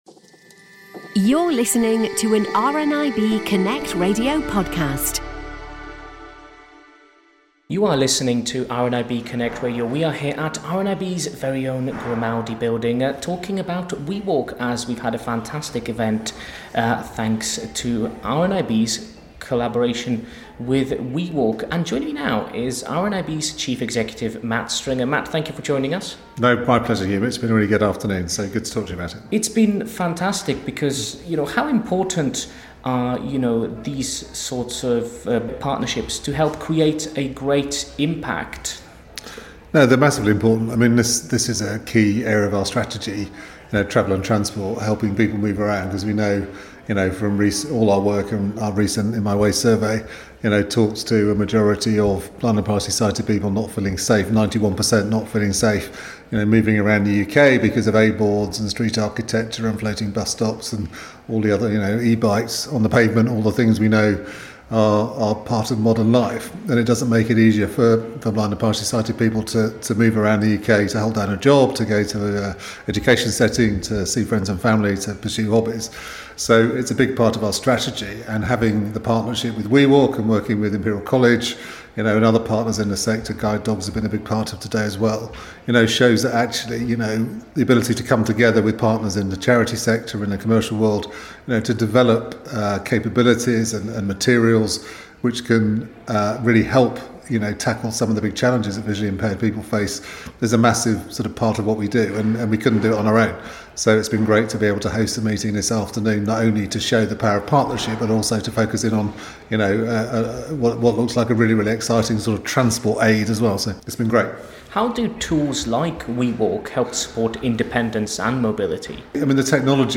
From the WeWalk event which took place at the RNIB Grimaldi Building in London